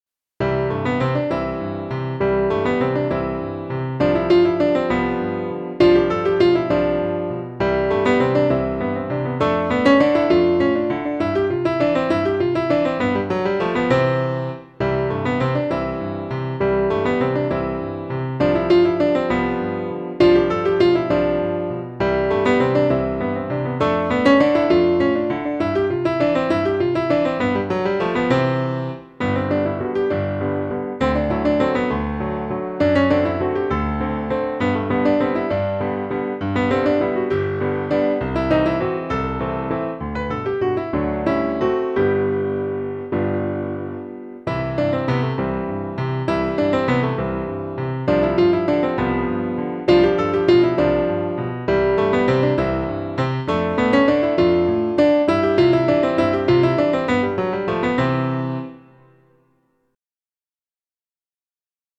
C Backing Track Available as mp3 download.
Classical and Opera